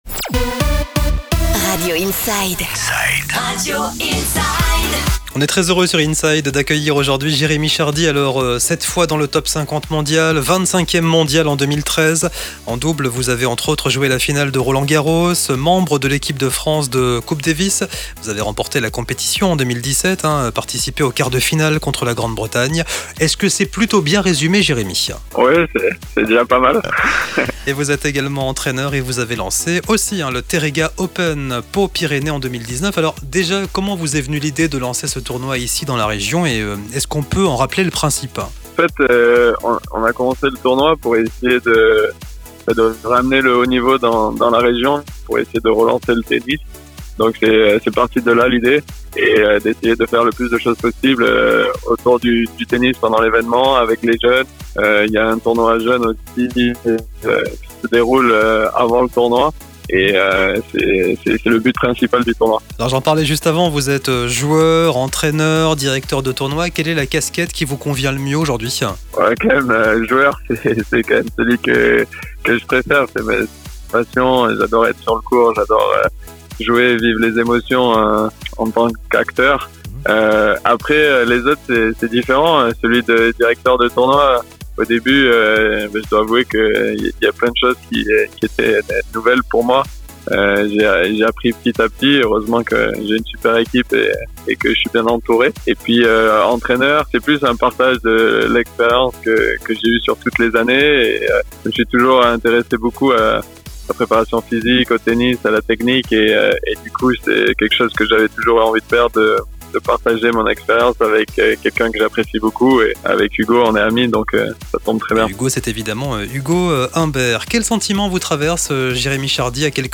Interview de Jeremy Chardy "Teréga Open" à Pau, sur Radio Inside